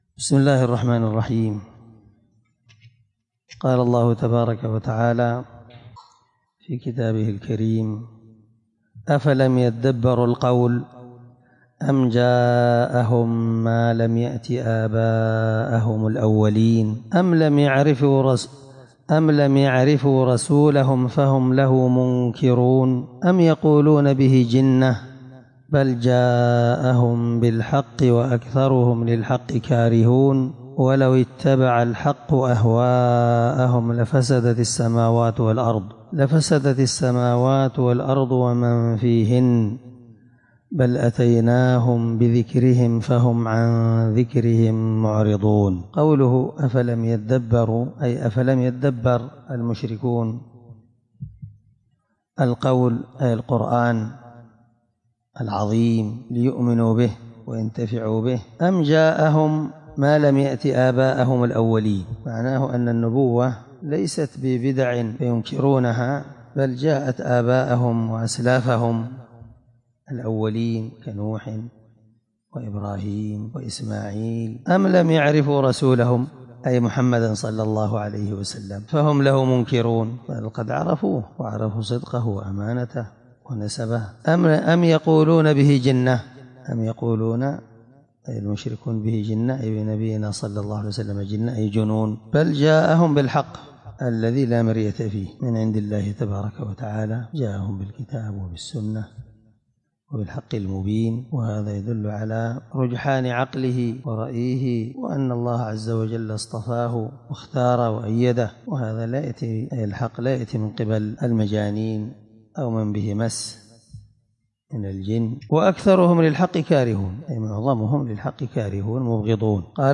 مع قراءة تفسير السعدي